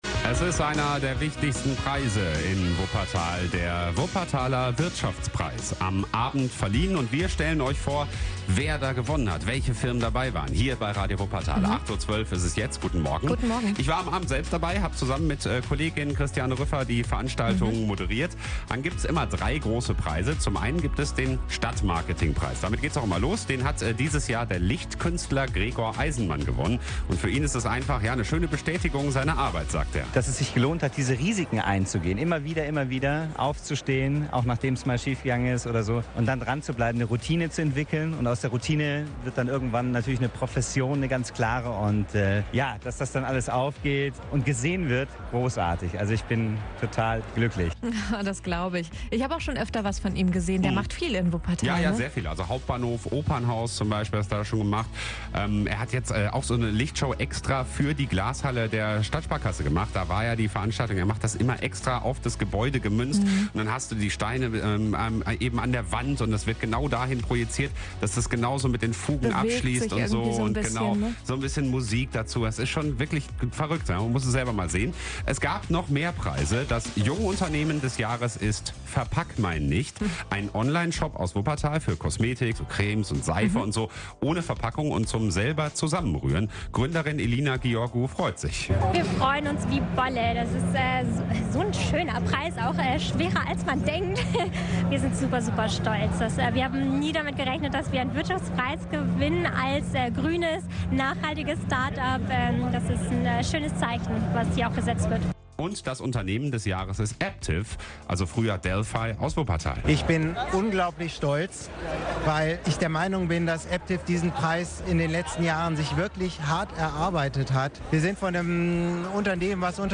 Am 27. Oktober 2021 wurden in der Glashalle der Sparkasse die Wuppertaler Wirtschaftspreise 2021 verliehen.